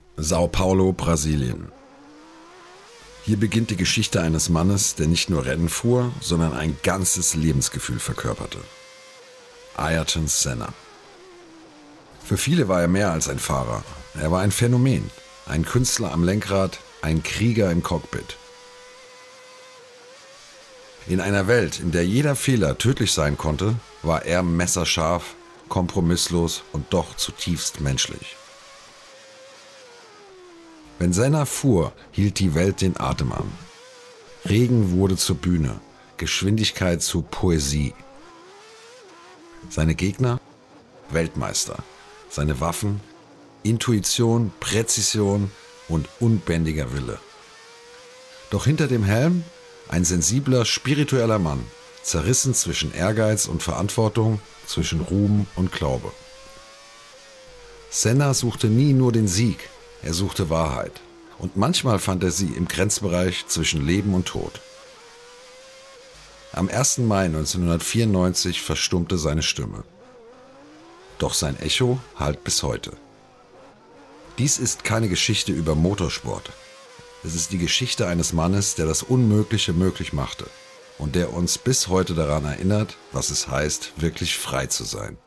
Male
My voice ranges from warm and deep to calm and engaging, making it appropriate for a variety of projects.
Documentary
German Documentary 2